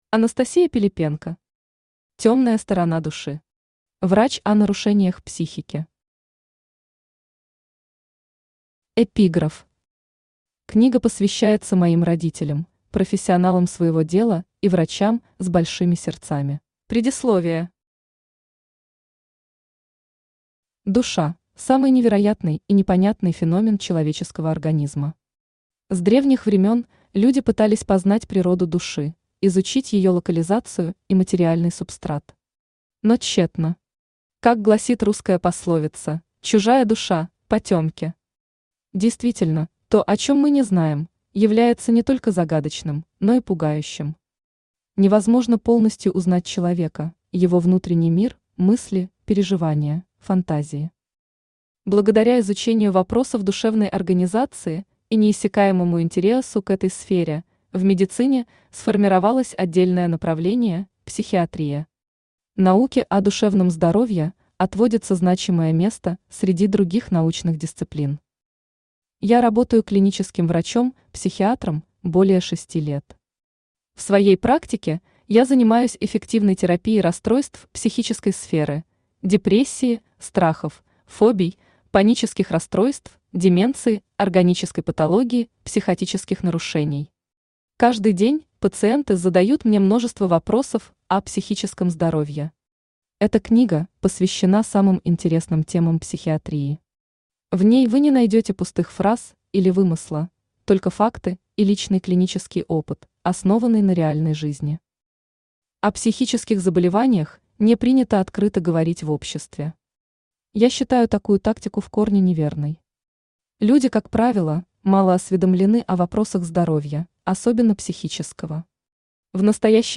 Аудиокнига Тёмная сторона души. Врач о нарушениях психики | Библиотека аудиокниг